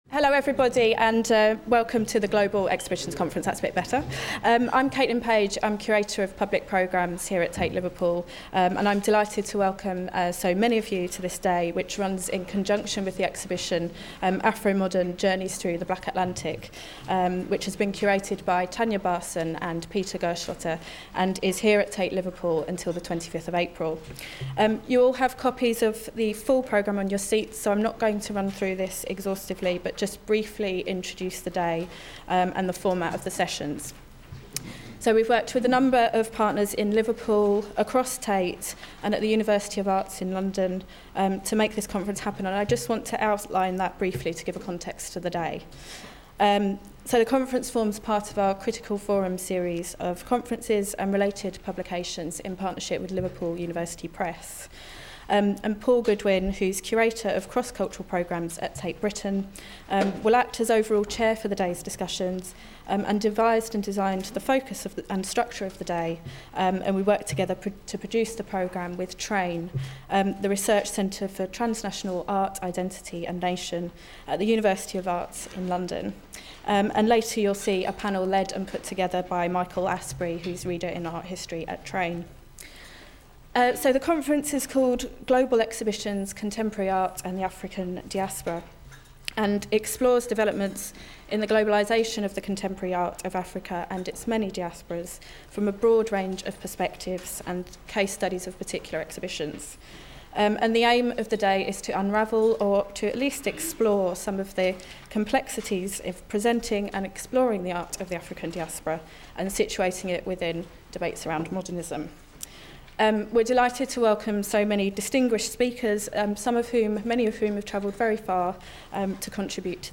Audio recordings of past Tate Modern conference, Global Exhibitions: Contemporary Art and the African Diaspora